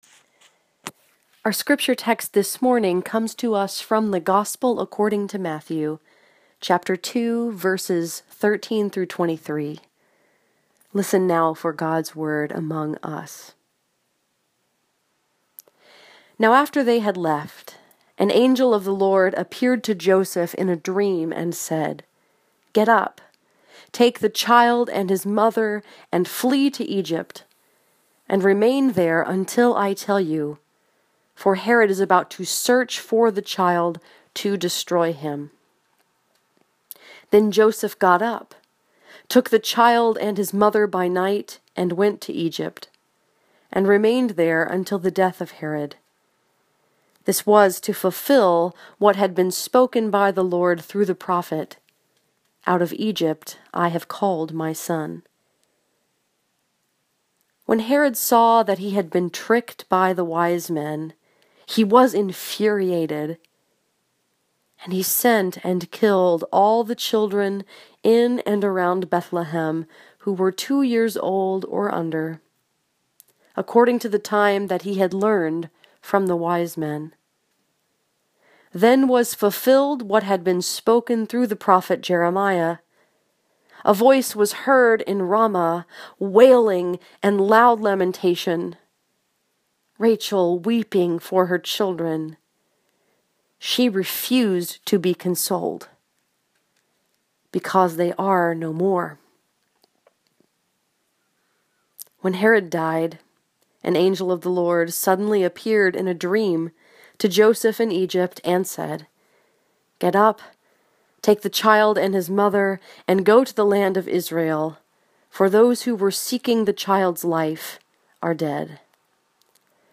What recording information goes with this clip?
This sermon was preached at Southminster Presbyterian Church in Taylor, Michigan and was focused upon Matthew 2:13-23.